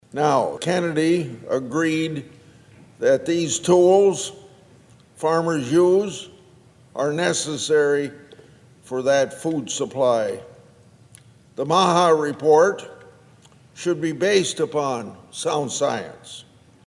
In comments this week, Iowa Senator Chuck Grassley adressed the concerns of the region's farmers. He said he spoke with Secretary Kennedy about the importance of pesticides in food production.